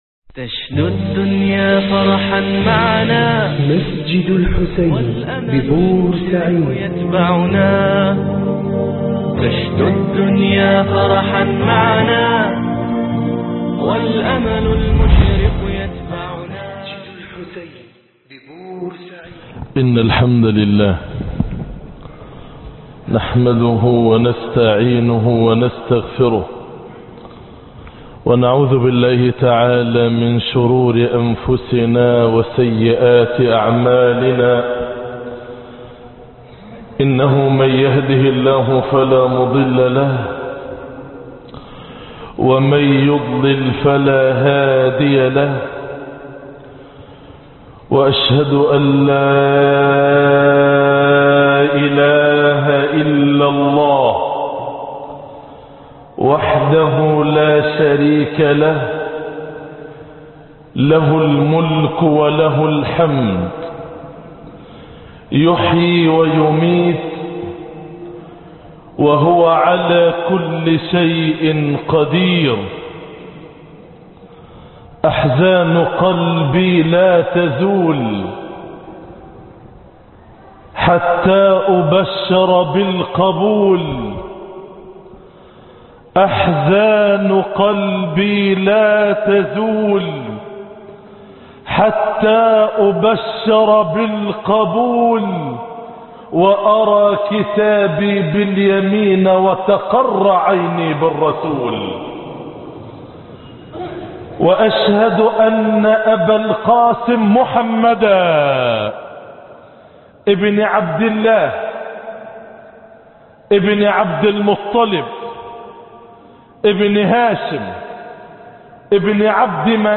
مسجد الحسين